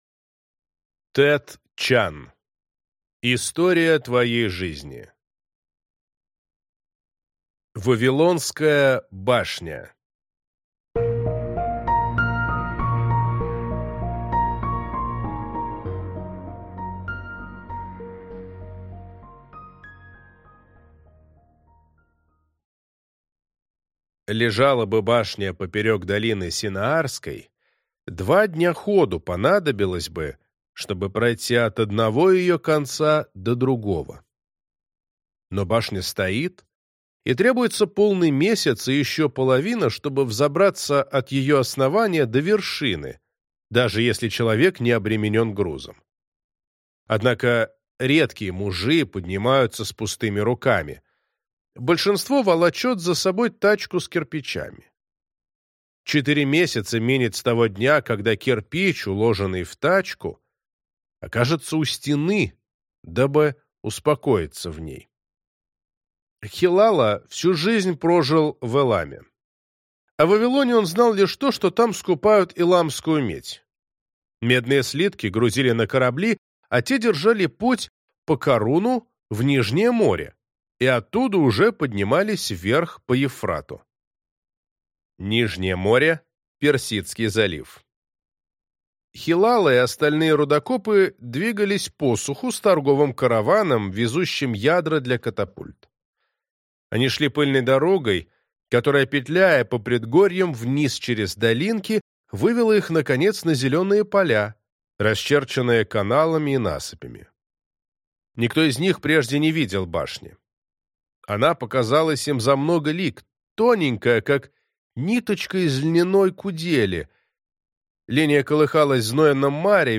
Аудиокнига История твоей жизни (сборник) | Библиотека аудиокниг